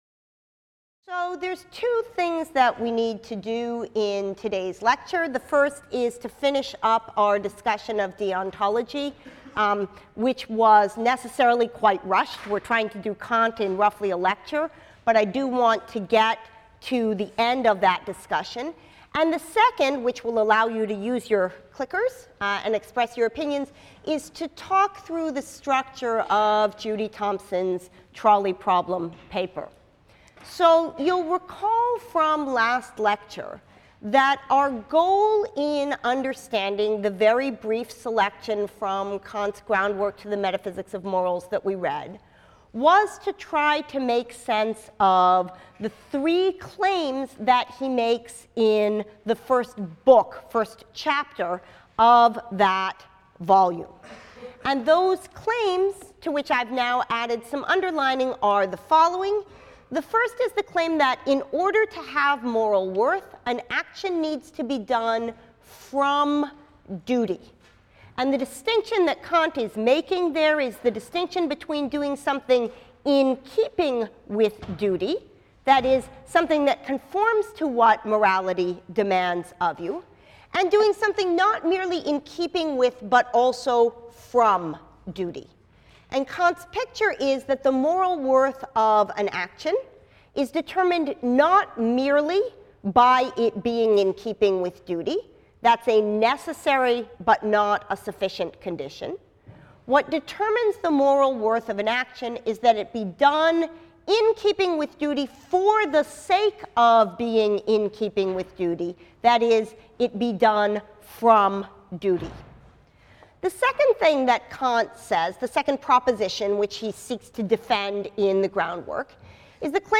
PHIL 181 - Lecture 14 - The Trolley Problem | Open Yale Courses